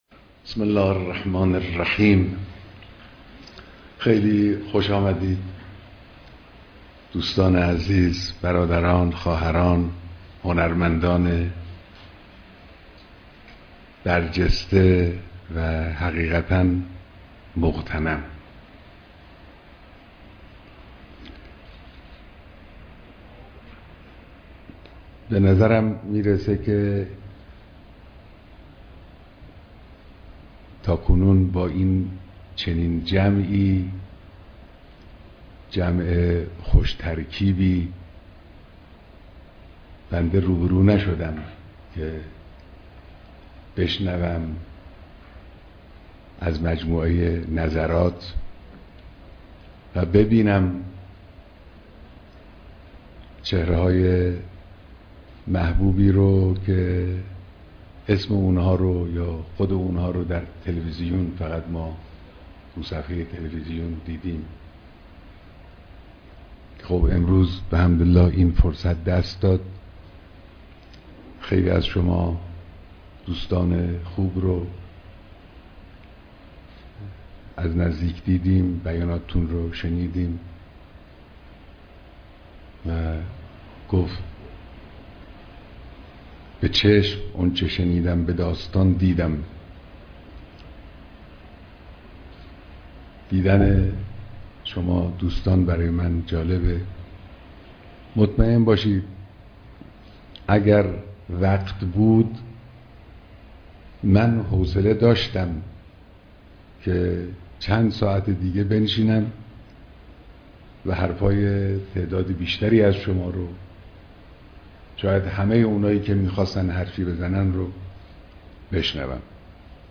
دیدار رئیس سازمان و جمعی از هنرمندان صدا و سیما